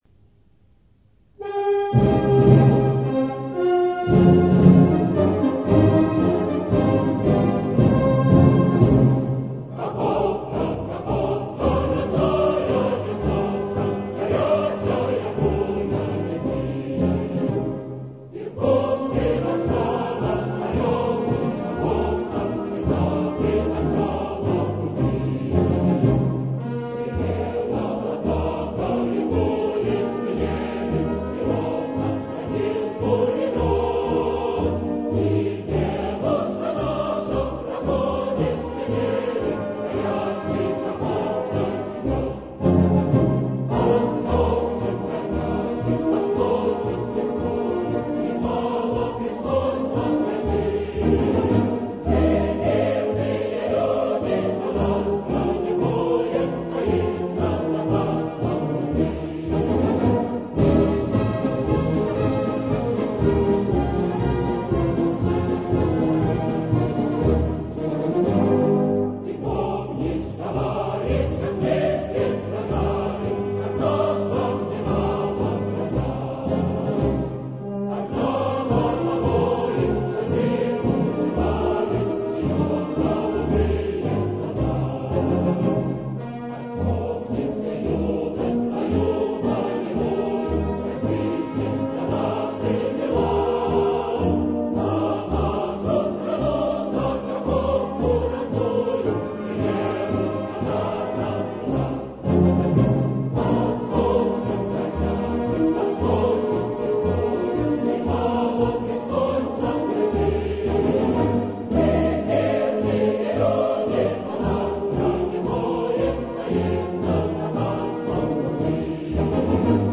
某合唱版